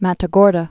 (mătə-gôrdə)